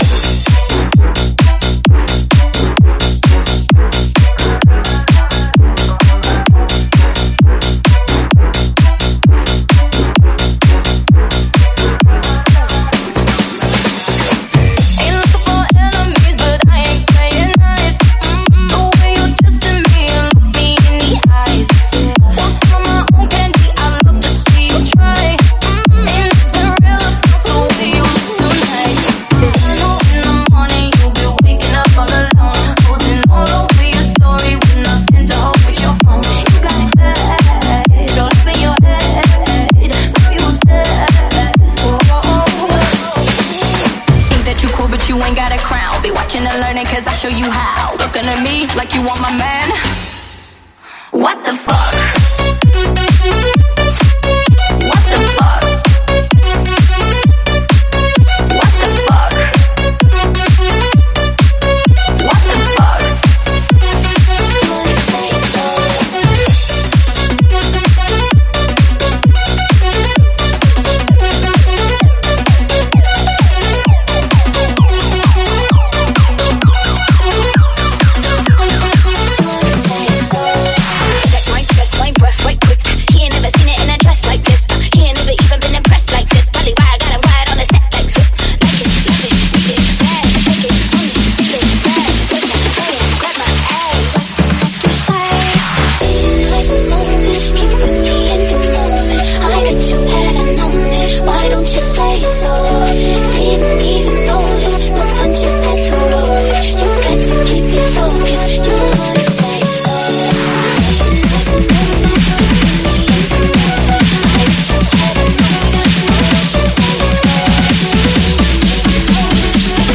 试听文件为低音质